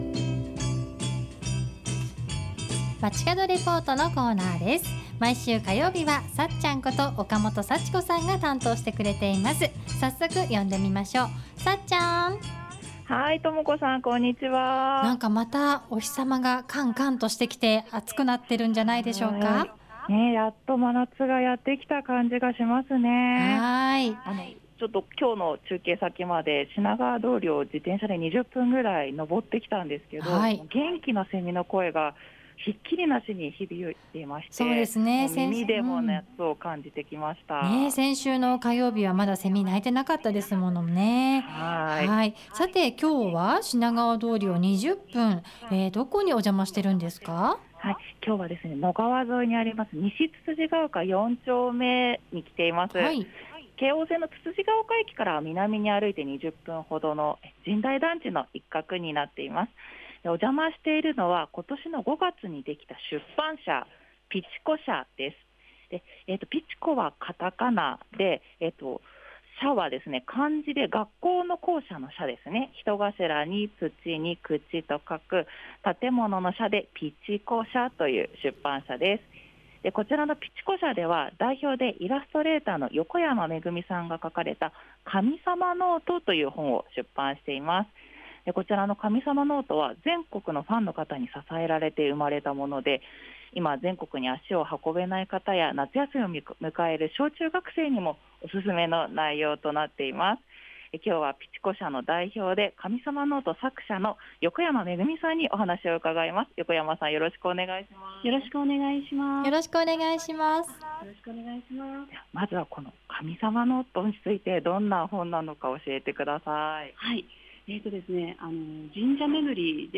街角レポート
中継は西つつじが丘4丁目に今年の5月に設立された出版社「ピチコ舎」にお邪魔しました。